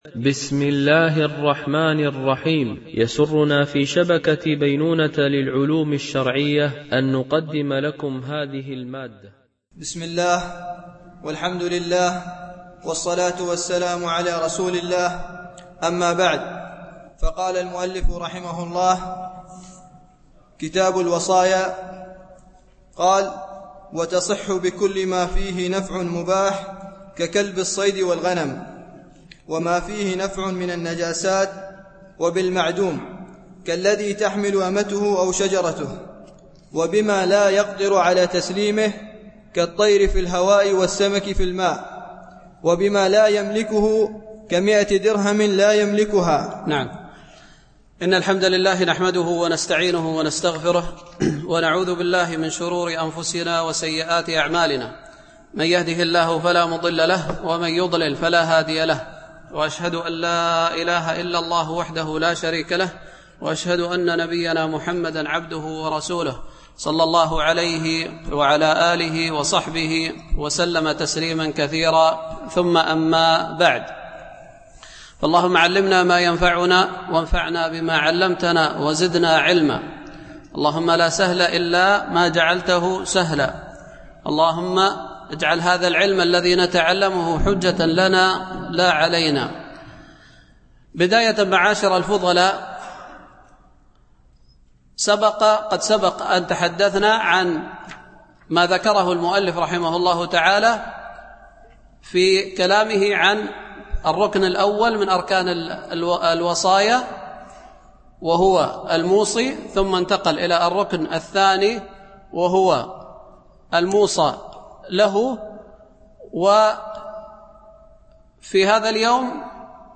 دورة الإمام مالك العلمية السادسة، بدبي